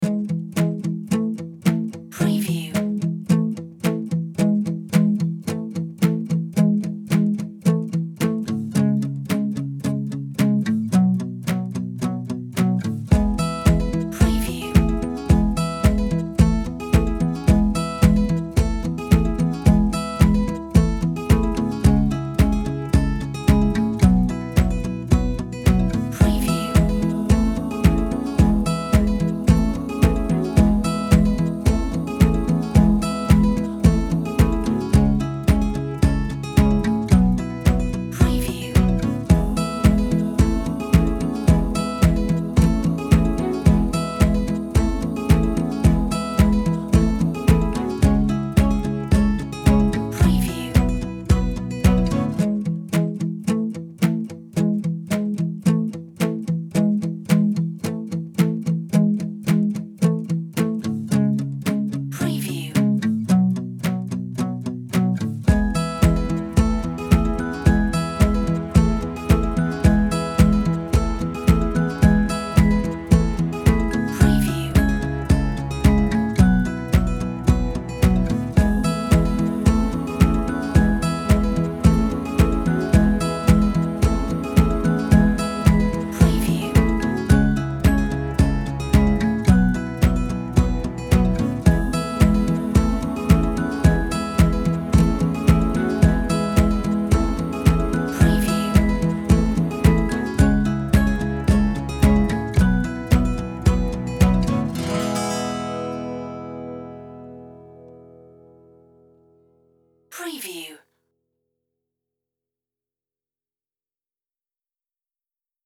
Simple and nice